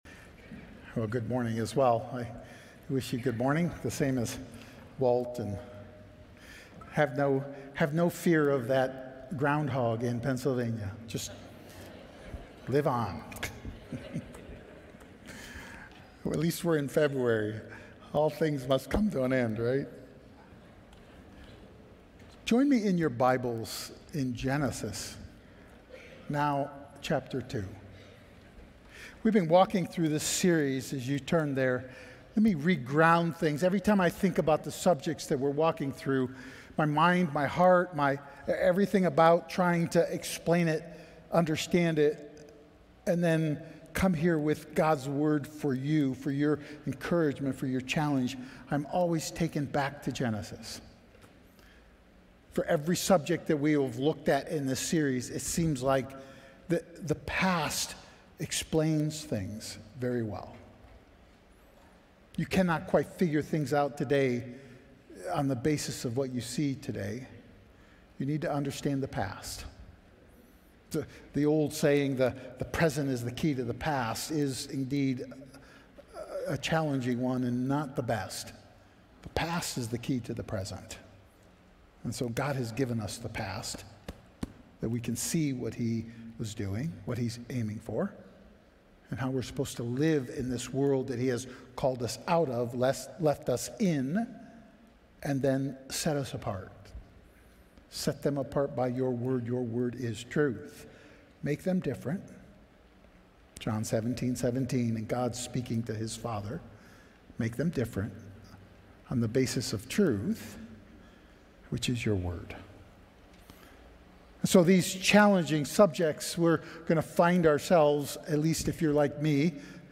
We are Audio File Sermon Notes More From This Series All We Need 2025-02-16 His Covenant 2025-02-09 His Design 2025-01-26 His Image 2025-01-19 His Creation 2025-01-12 His Words 2025-01-05